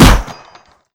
Gun Shoot.wav